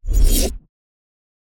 cast-generic-01.ogg